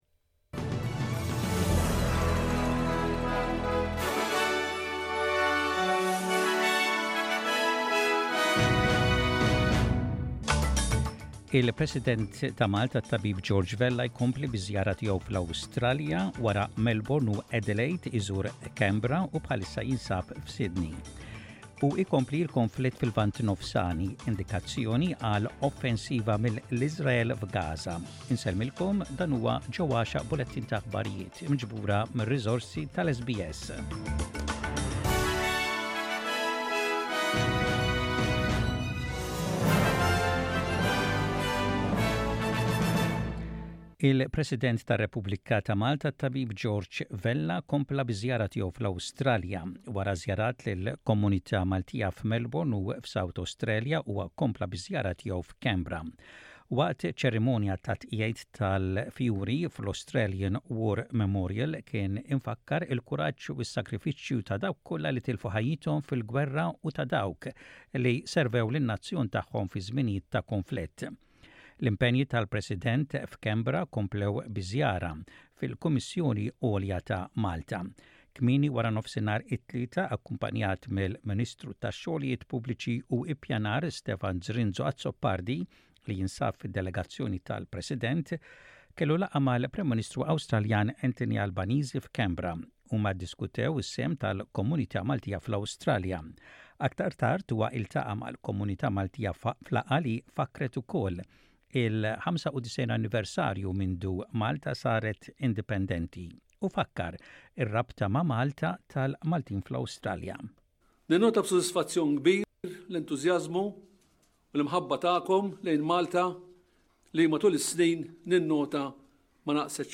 SBS Radio | Maltese News: 20.10.23